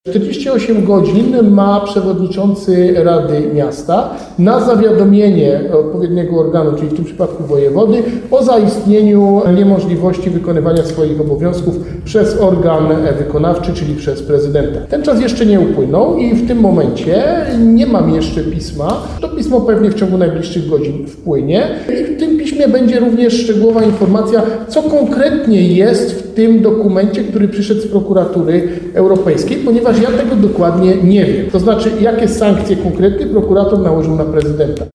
Kto będzie rządził w Nowym Sączu? Wojewoda odpowiada na nasze pytania